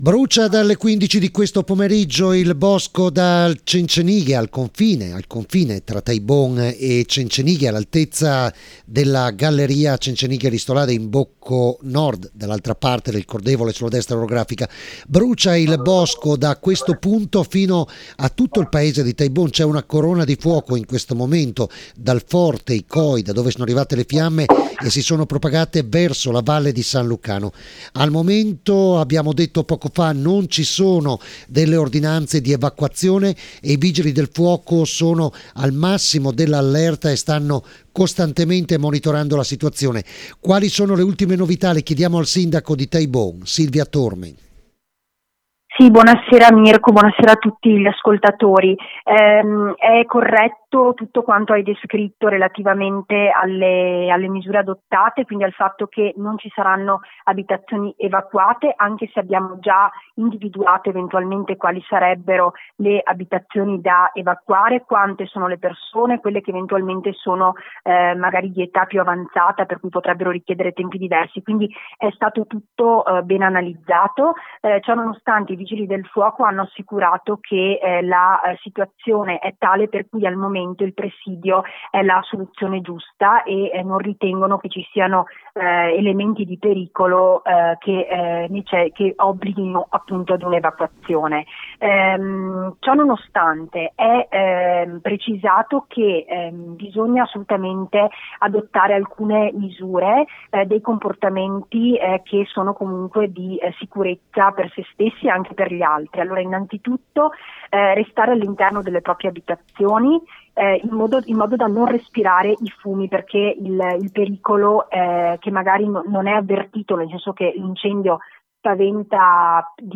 L’INTERVISTA AL CENTRO MOBILE DI COORDINAMENTO DEL CNSAS A TAIBON